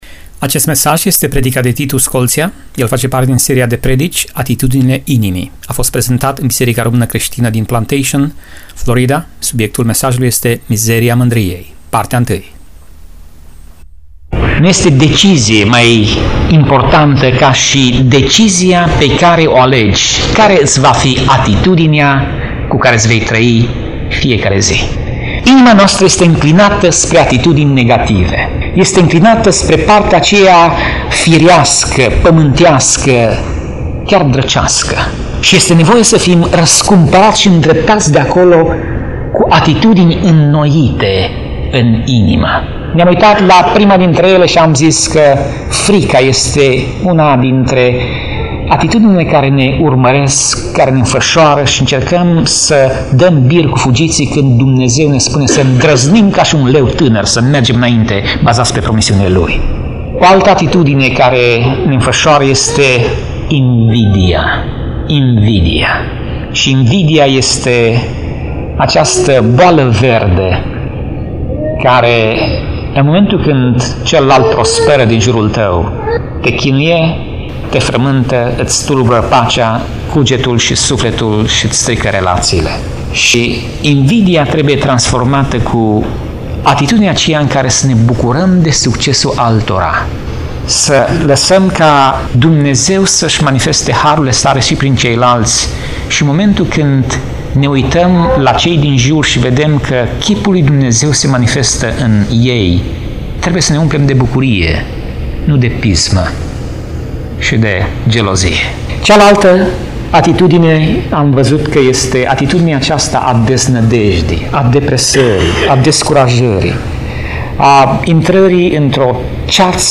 Tip Mesaj: Predica Serie: Atitudinile inimii